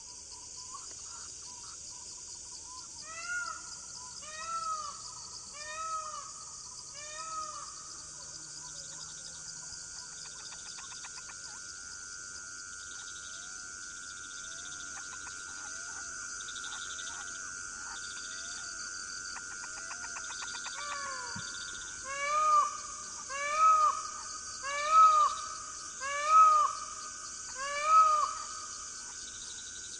Paon bleu - Mes zoazos
paon-bleu.mp3